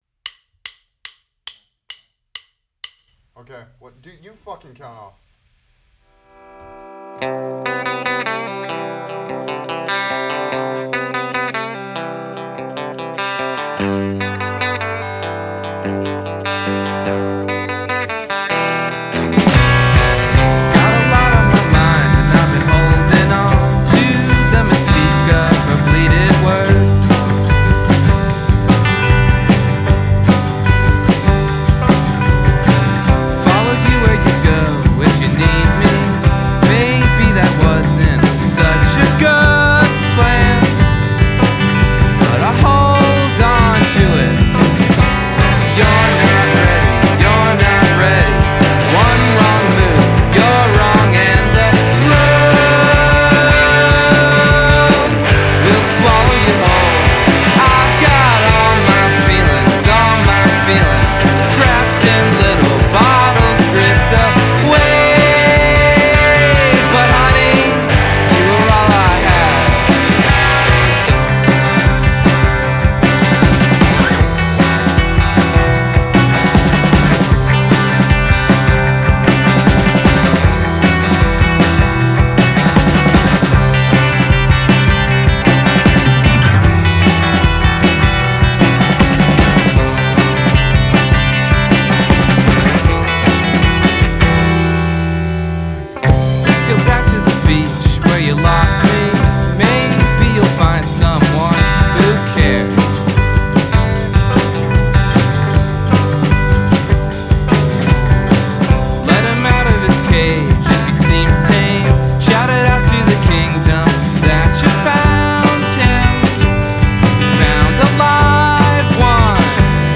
Amateurs d'indie noisy pop voici un disque pour vous !